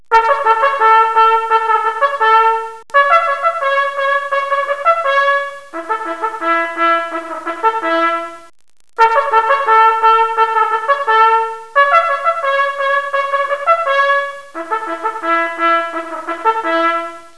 Bugle Calls
Bugle calls are musical signals that announce scheduled and certain non-scheduled events on an Army installation.